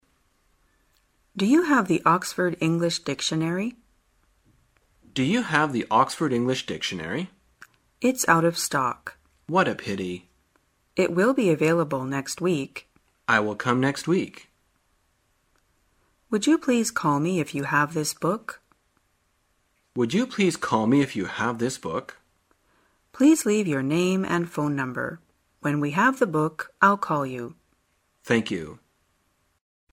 在线英语听力室生活口语天天说 第37期:怎样表达没货与到货的听力文件下载,《生活口语天天说》栏目将日常生活中最常用到的口语句型进行收集和重点讲解。真人发音配字幕帮助英语爱好者们练习听力并进行口语跟读。